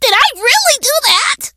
meg_kill_vo_05.ogg